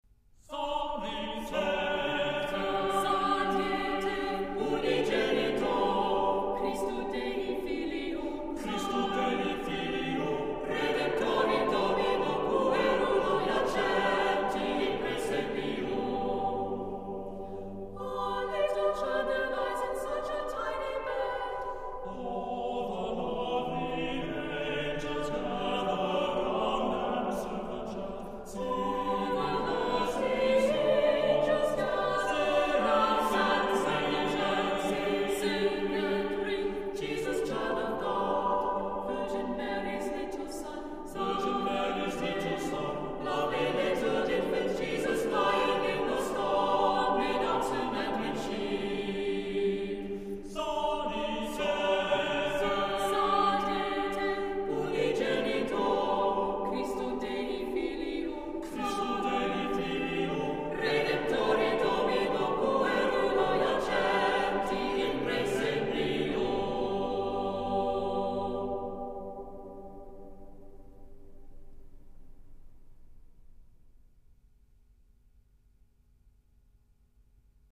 Accompaniment:      A Cappella
Music Category:      Early Music